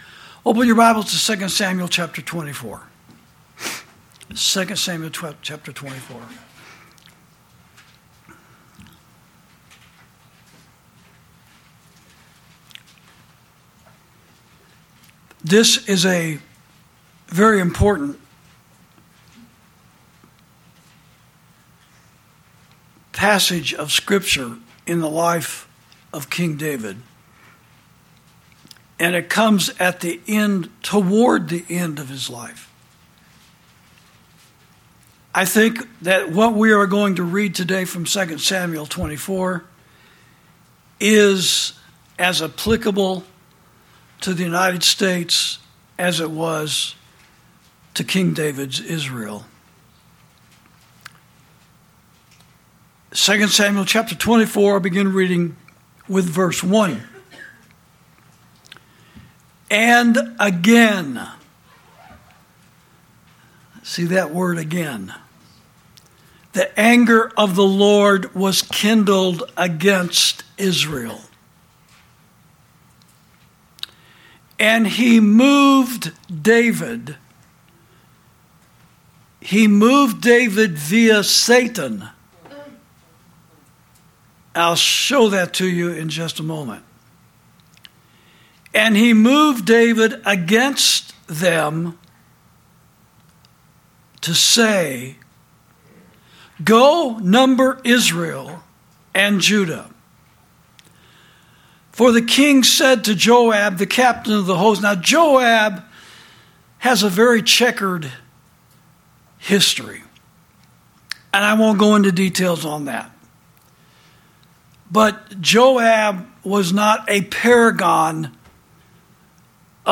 Sermons > Religion That Costs Nothing Is Worth Nothing